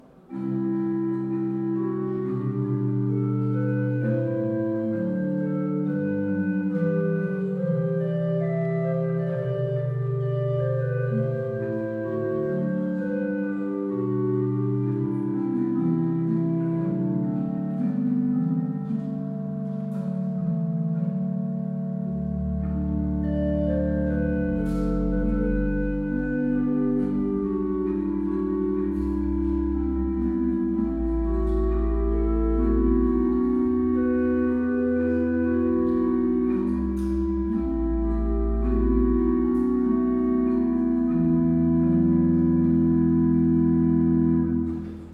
kostel sv. Jana Nepomuckého
Nahrávky varhan:
Vsemina, Rohrflauta 8.mp3